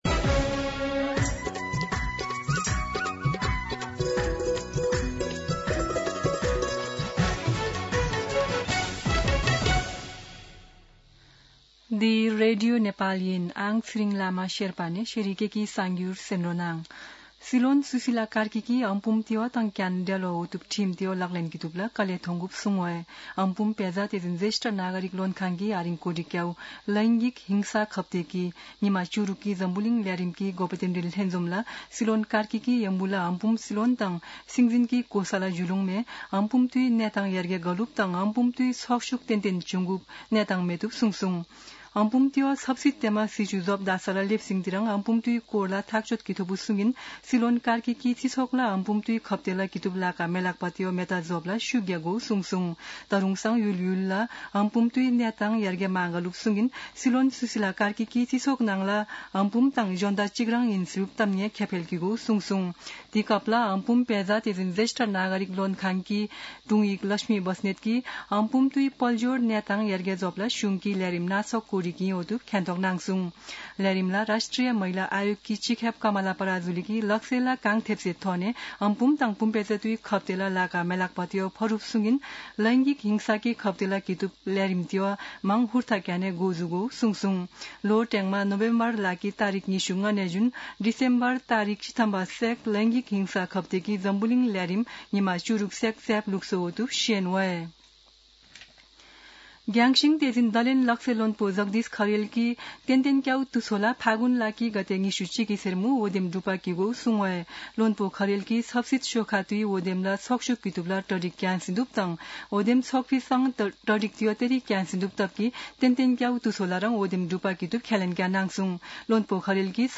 शेर्पा भाषाको समाचार : ९ मंसिर , २०८२
Sherpa-News-8-9.mp3